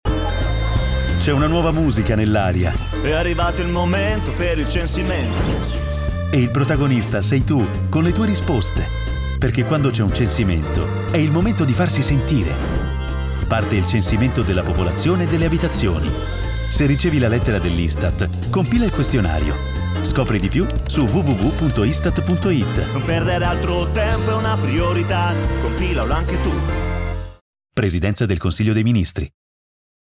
Lo spot radio
2025_istat_av_censimento_popolazione_radio_rai.mp3